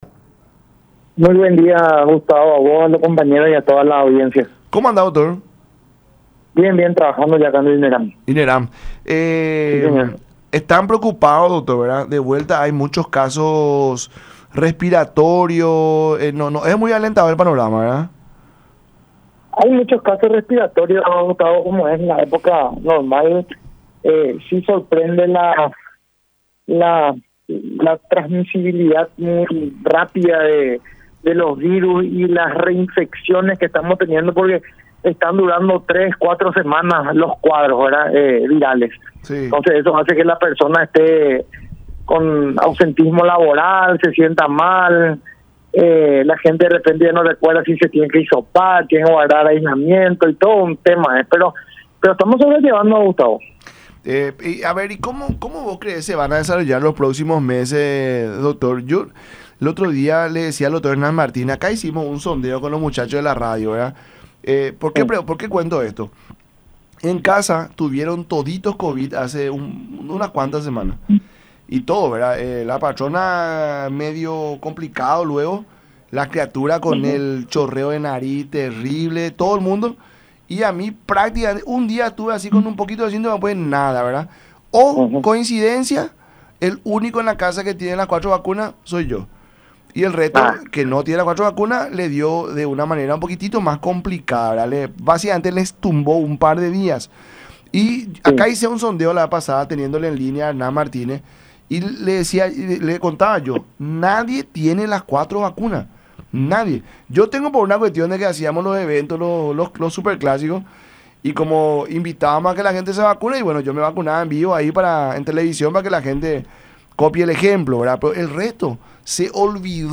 en diálogo con La Mañana De Unión